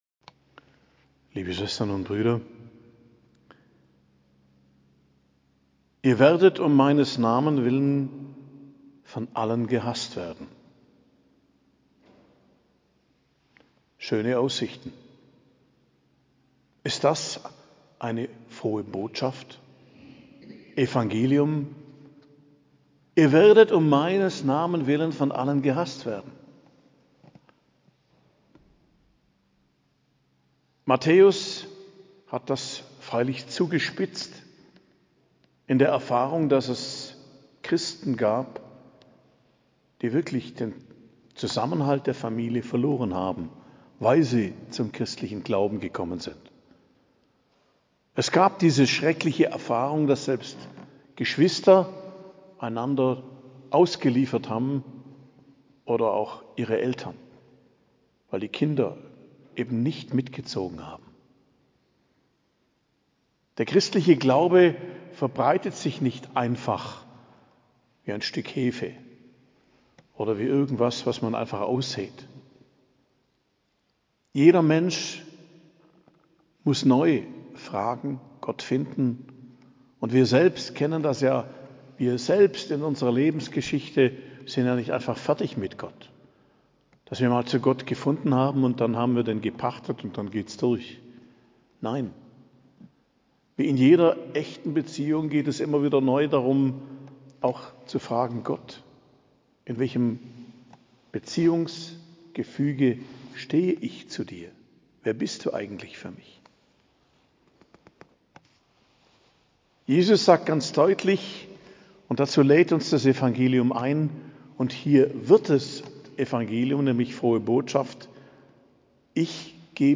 Predigt am Freitag der 14. Woche i.J., 14.07.2023